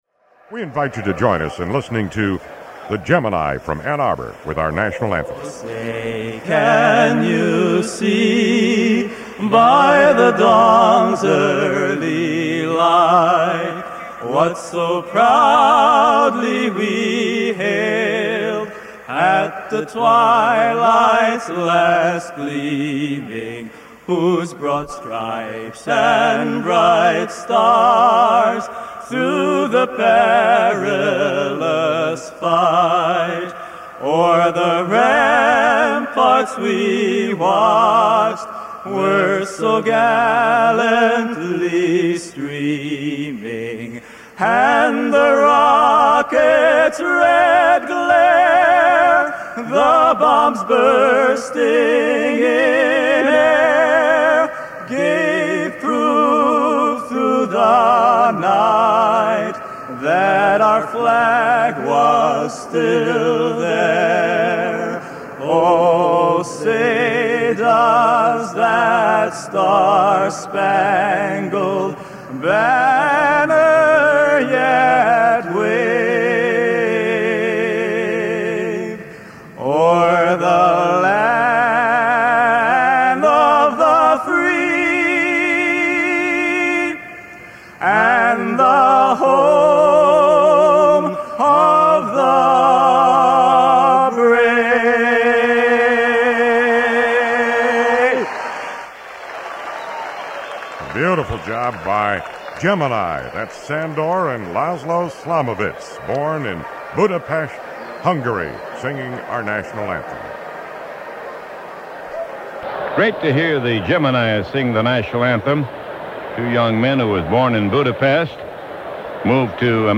recording that a friend of ours made of their comments on one of those radio broadcasts, is another treasured memento.